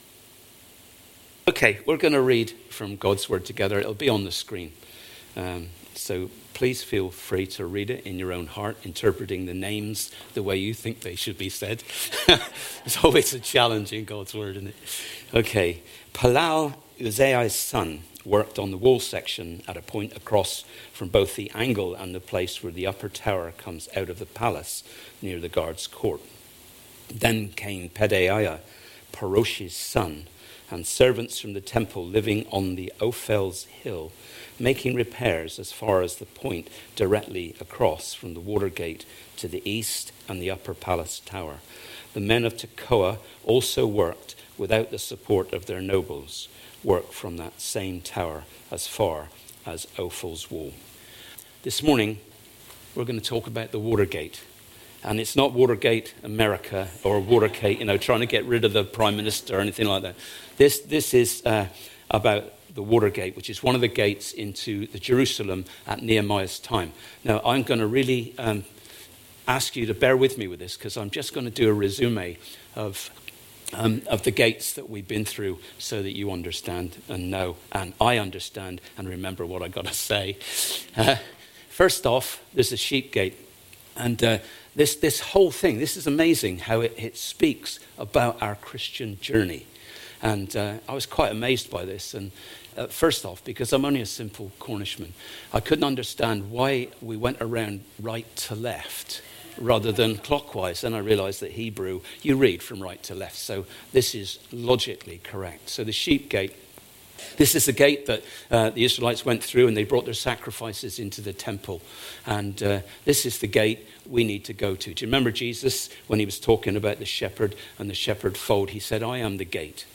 Apologies, the end of the sermon was not recorded owing to a faulty CD. 1) 29 April 2018 slides 2) 29 April 2018 sermon